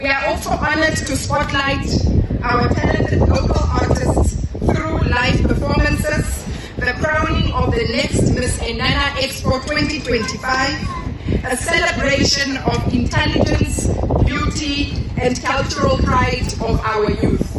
Ohangwena Governor Kadiva Hamutumua says the expo is focused on youth empowerment, entrepreneurship, sport, arts, and health.
ENG-Kadiva-Hamutumua-expo.mp3